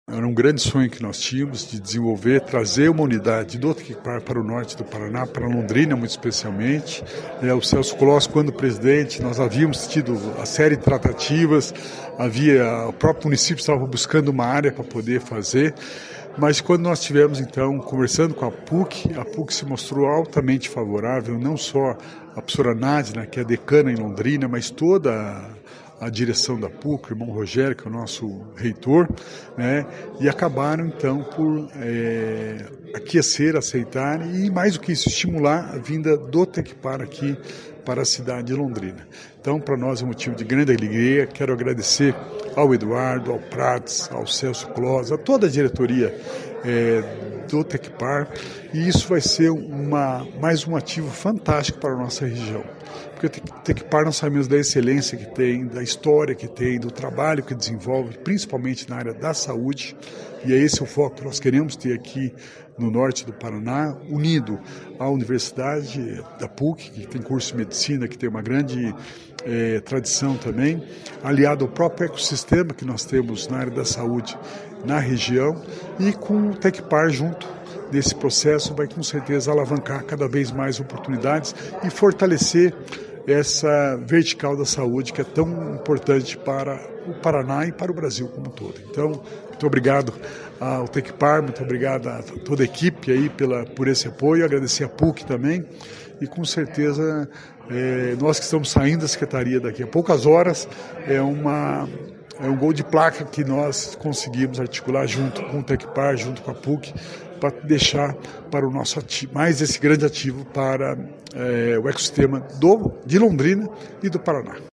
Sonora do secretário da Inovação e Inteligência Artificial, Alex Canziani, sobre o lançamento da pedra fundamental do Centro de Inovação Tecnológica do Tecpar de Londrina